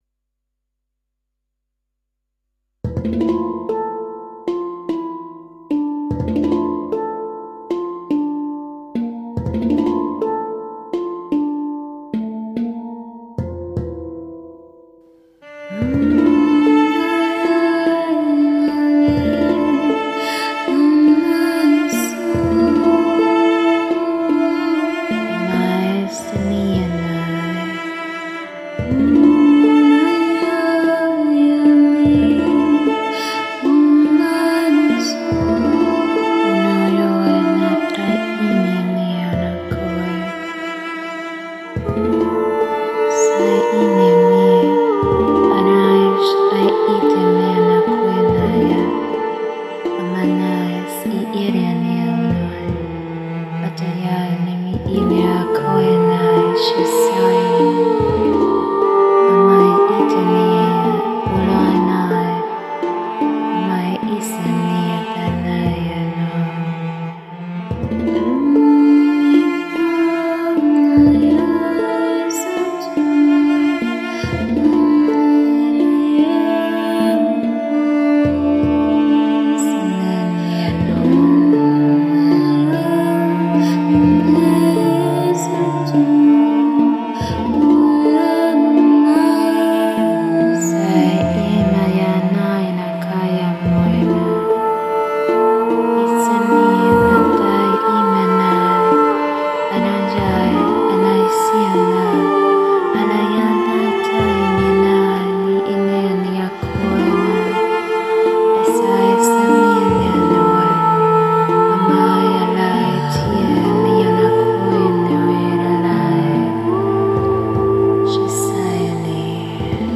Este cántico en Lenguaje de Luz que te obsequio servirá de portal a restaurar toda tu vibración corporal, mental y espiritual.